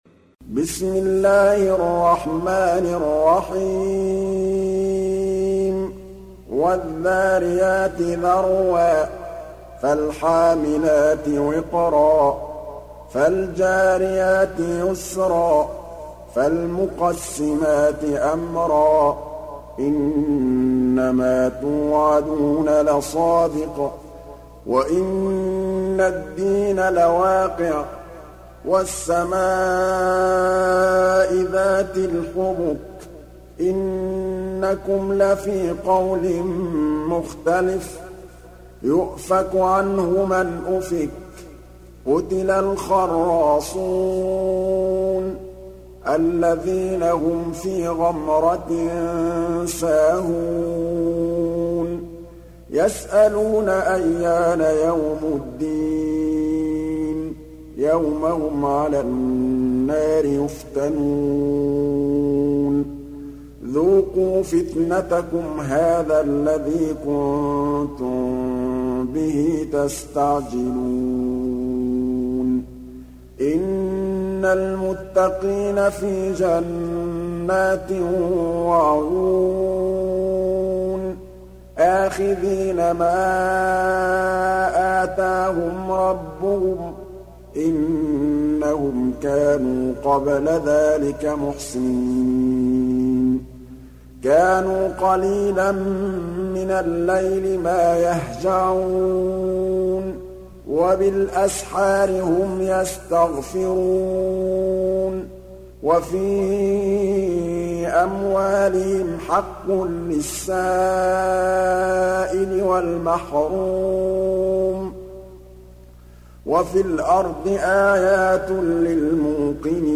تحميل سورة الذاريات mp3 بصوت محمد محمود الطبلاوي برواية حفص عن عاصم, تحميل استماع القرآن الكريم على الجوال mp3 كاملا بروابط مباشرة وسريعة